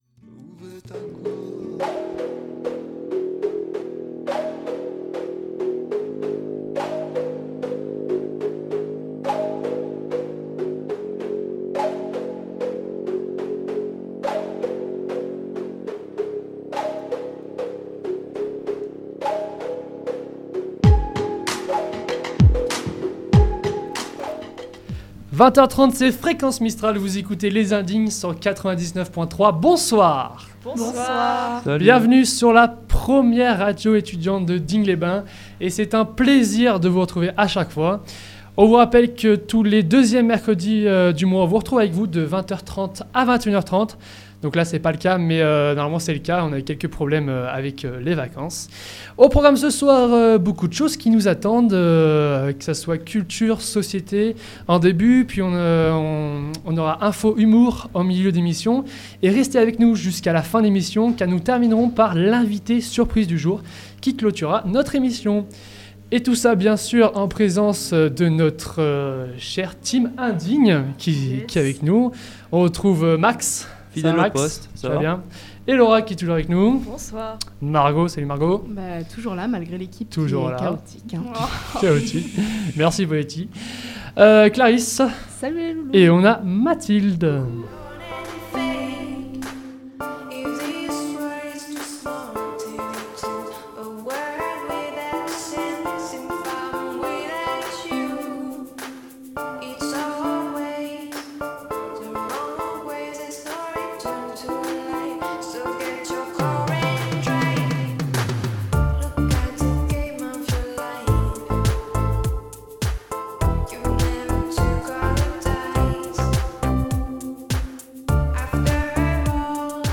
Les indignes 6 - infos - humour - interview -
Au programme des jeux, des infos, de la musique... mais pas seulement, des sujets divers et variés d'actualités, des conseils de livres ou encore de films etc. Ils sont dynamiques, joyeux et super motivés.